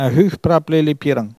Elle crie pour appeler les oisons
Catégorie Locution